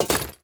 Equip_netherite1.ogg.mp3